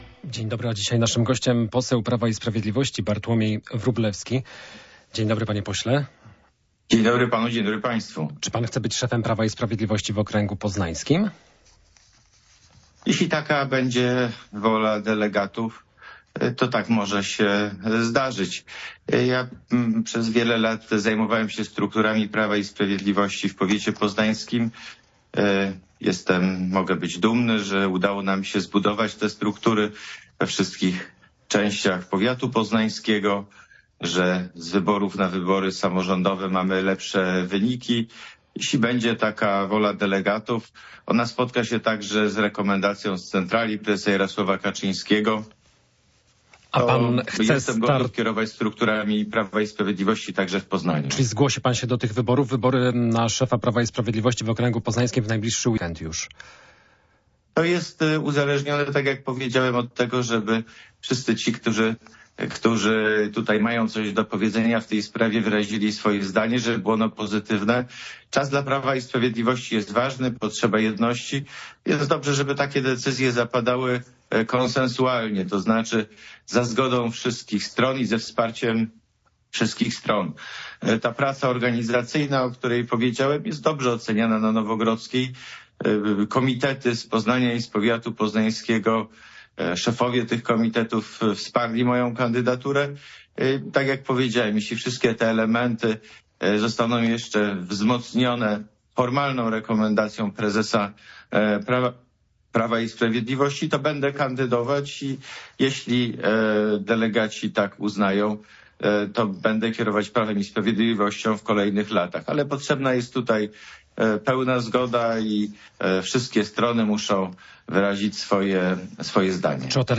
Kto zostanie szefem Prawa i Sprawiedliwości w okręgu poznańskim? Gościem Radia Poznań jest poseł PIS Bartłomiej Wróblewski.